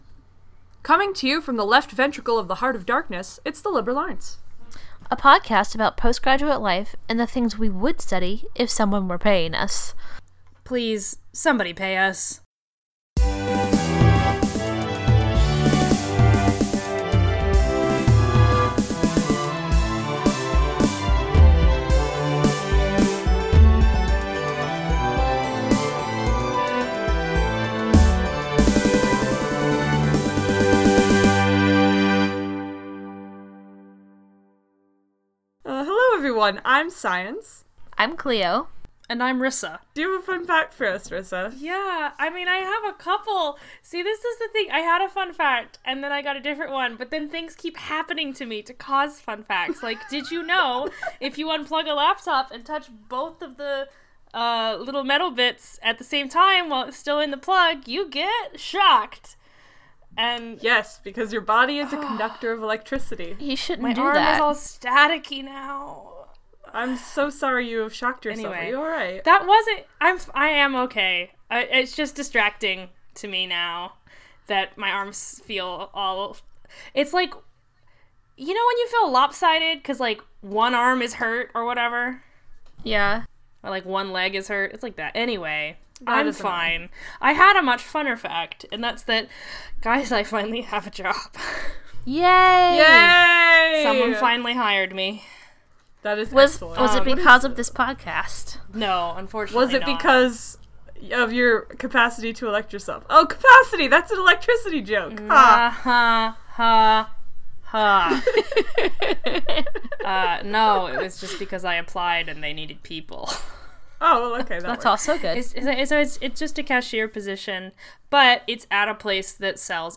Do you hear those voices?